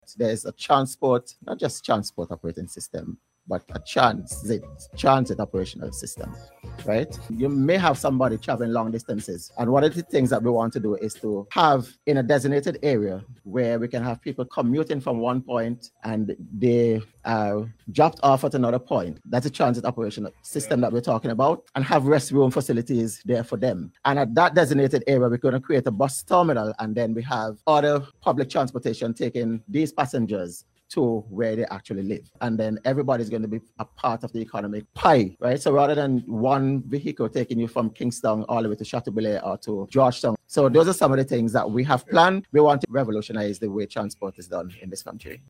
Word of this came from Minister of Transport, Infrastructure, and Physical Planning, Hon. Nigel Stephenson, as he outlined plans for a transformative transit system.